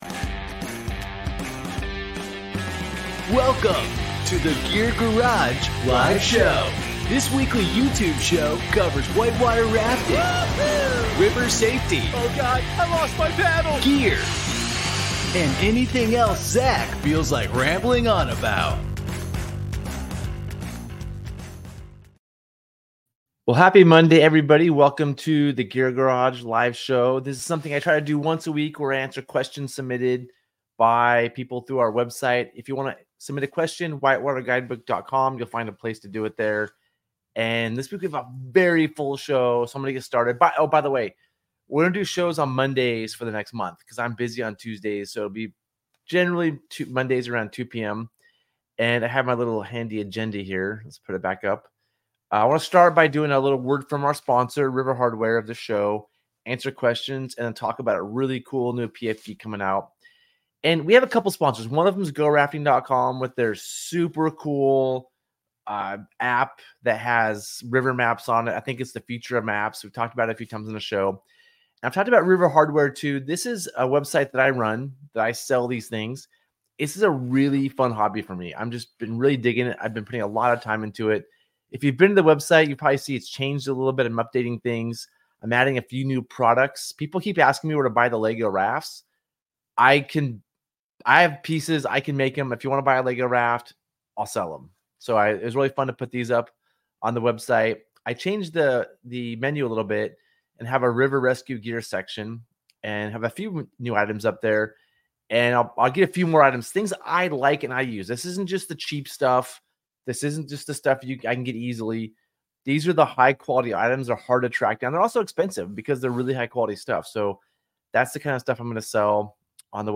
This podcast is the audio version of the Gear Garage Live Show, where we answer submitted questions and talk all things whitewater.